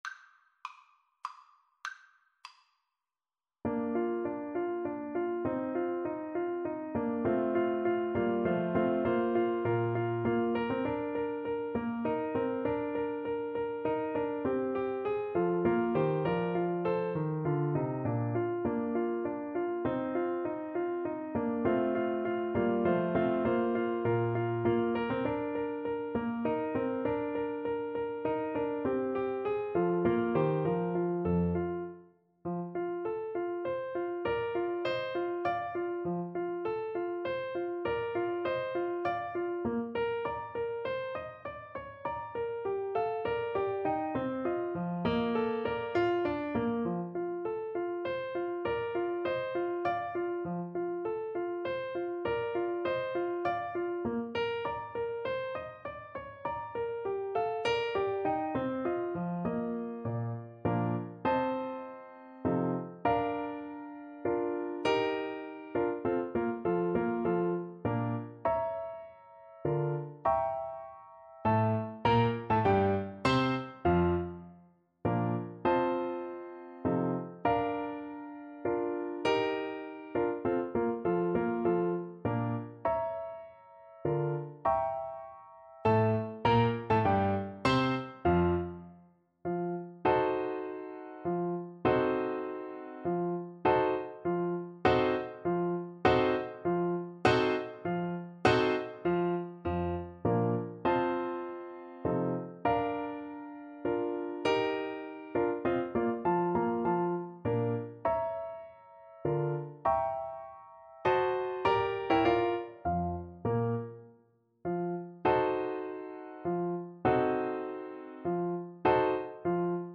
Menuetto Moderato e grazioso
3/4 (View more 3/4 Music)
Classical (View more Classical Clarinet Music)